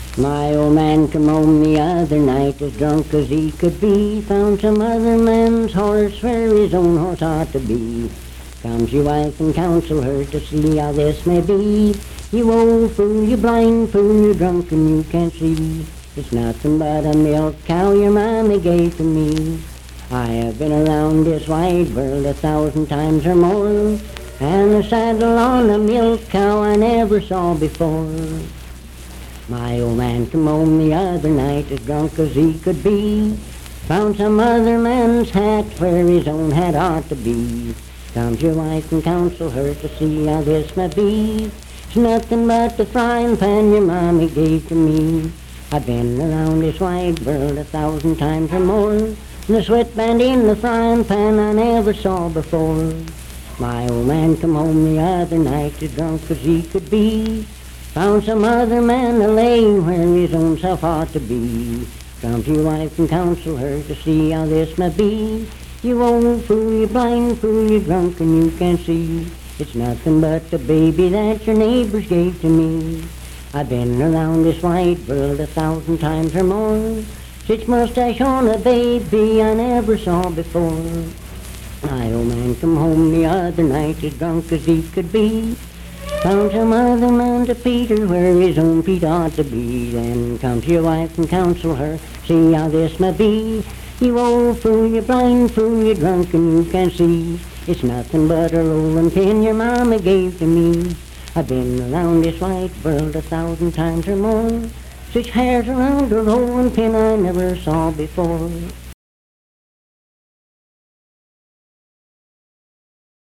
Unaccompanied vocal music
Verse-refrain 4(14w/R).
Performed in Sandyville, Jackson County, WV.
Bawdy Songs, Marriage and Marital Relations, Humor and Nonsense
Voice (sung)